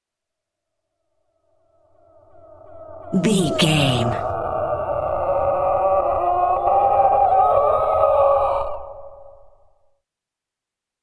Ghost wind
Sound Effects
In-crescendo
Atonal
scary
ominous
haunting
eerie